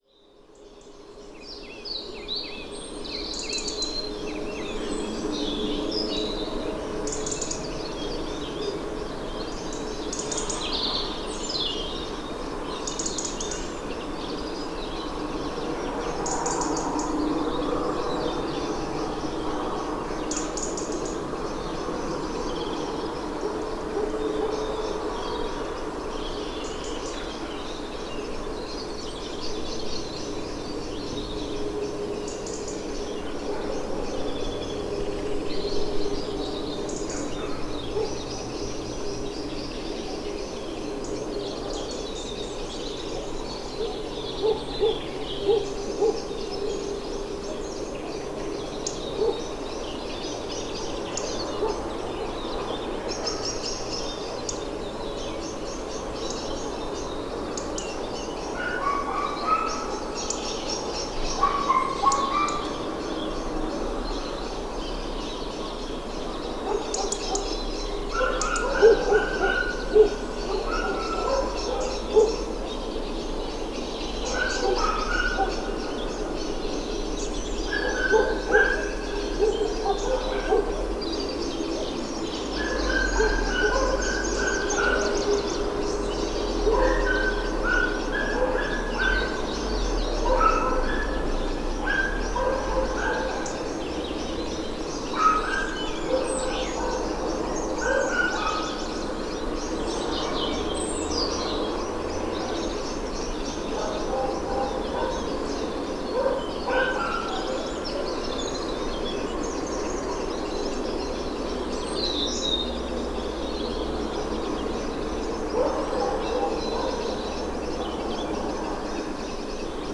Paisagem sonora ao anoitecer na floresta junto à Estrada Municipal em Pousa Maria, Lordosa a 8 Março 2016.
A escuta ao anoitecer na floresta junto à Estrada Municipal em Pousa Maria assinala o fim de um dia soalheiro, talvez o fim do Inverno.